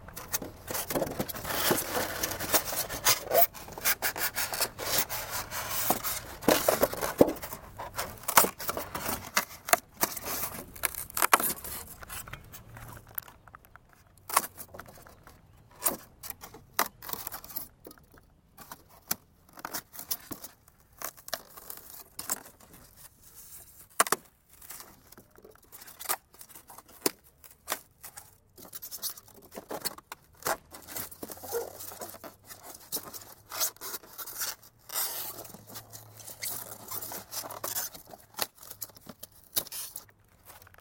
Звуки пенопласта
Звук контакта с пенопластом